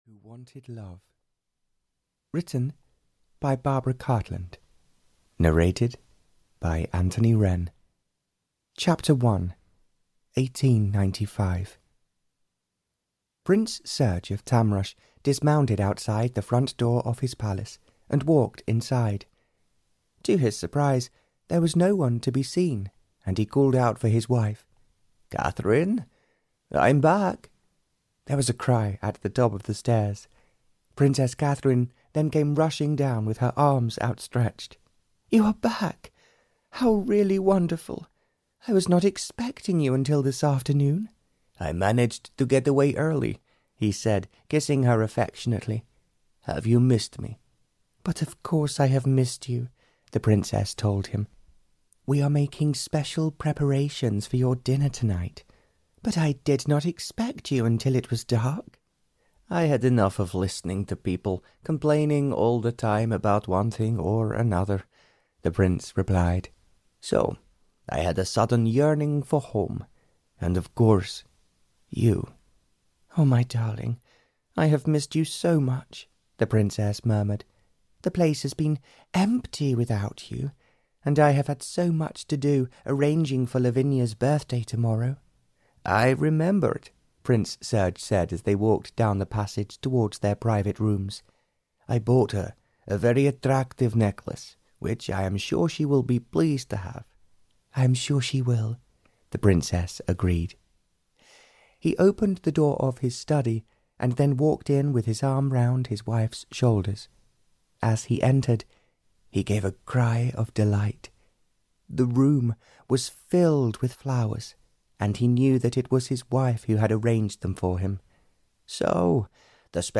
The Prince Who Wanted Love (EN) audiokniha
Ukázka z knihy